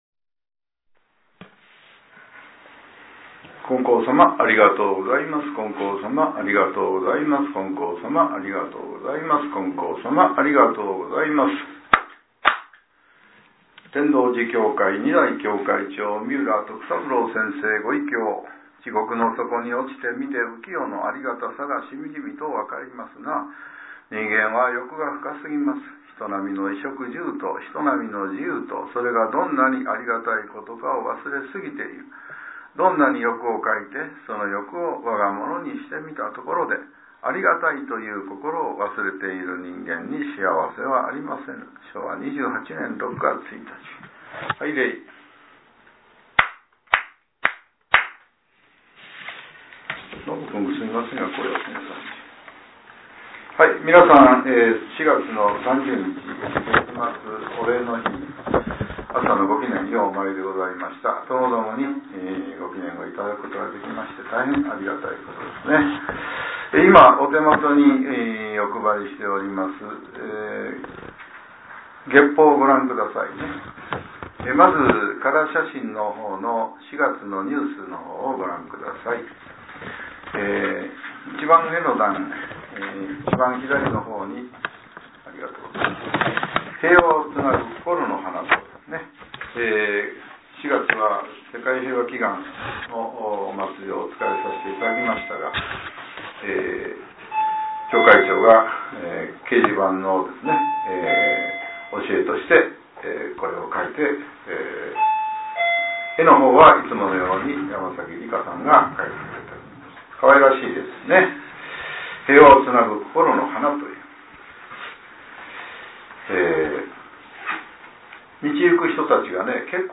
令和７年４月３０日（朝）のお話が、音声ブログとして更新されています。